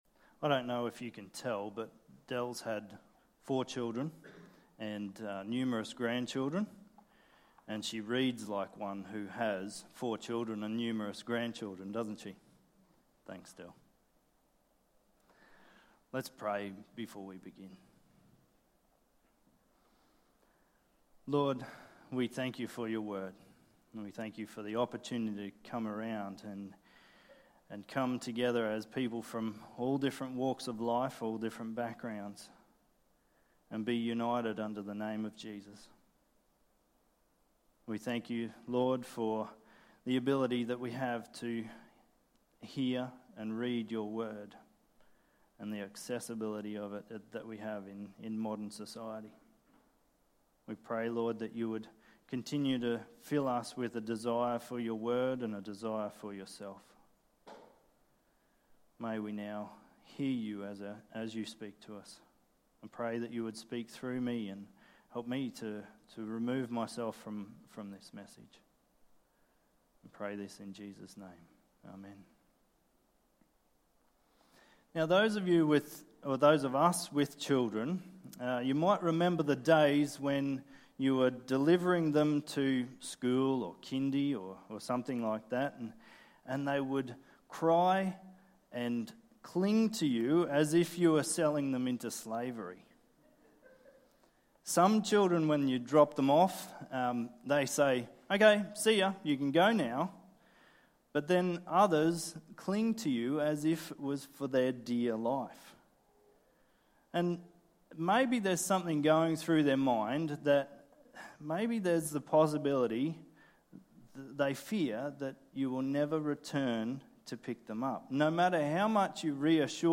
Sermons | Tenthill Baptist Church
17/10/21 Sunday Live Service